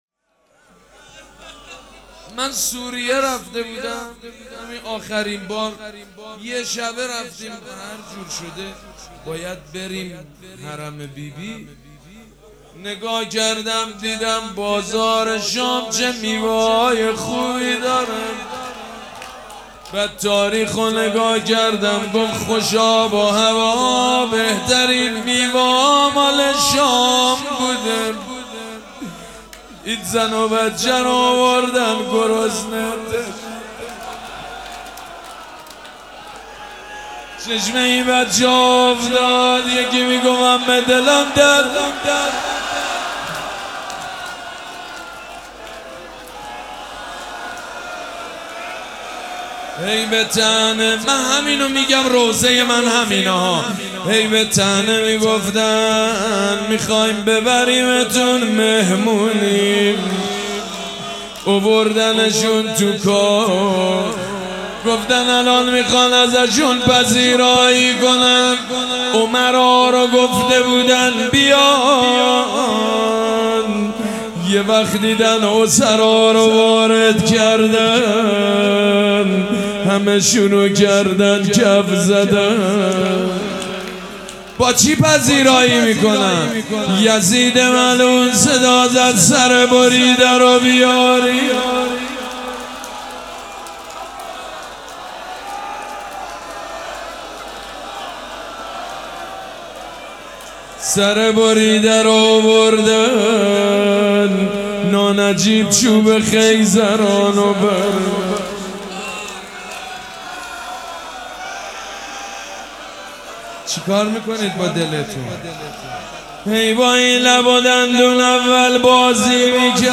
روضه
مداح
مراسم عزاداری شب اول